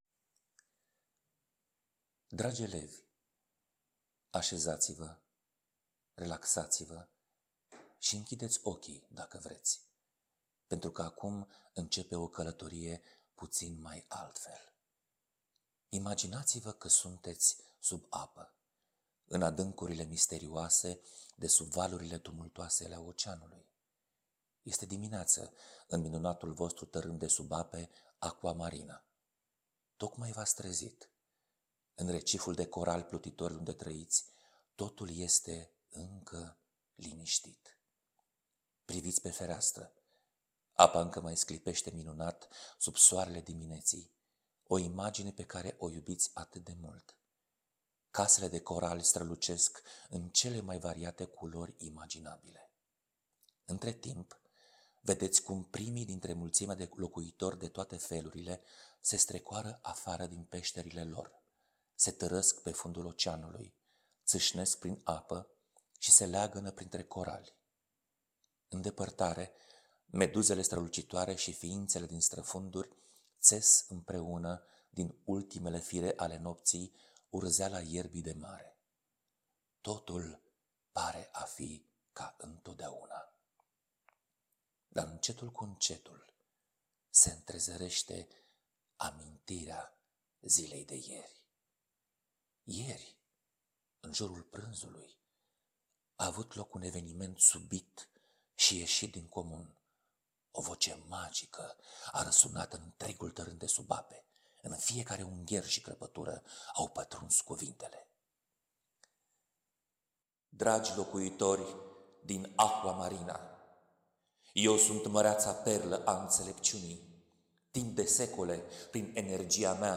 Conținutul nu este diferit, dar se recomandă povestea audio, deoarece prezintă mai viu atmosfera poveștii.